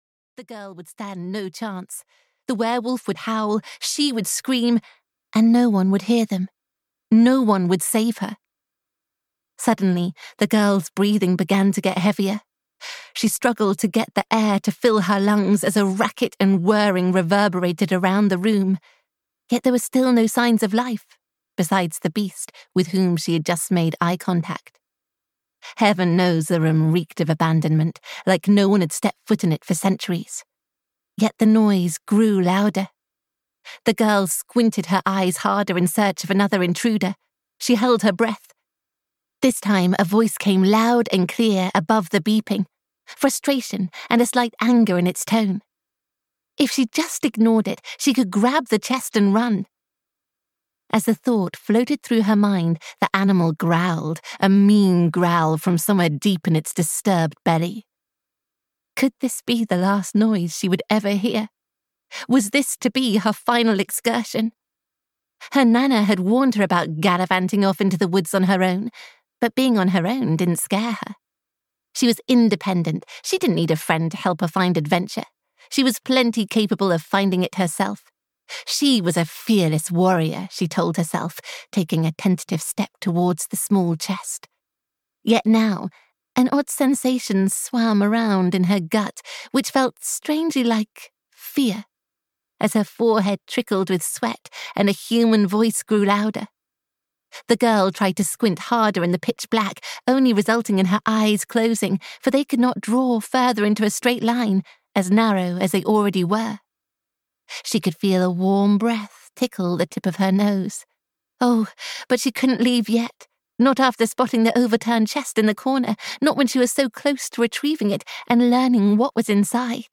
The Little Barn of Dreams (EN) audiokniha
Ukázka z knihy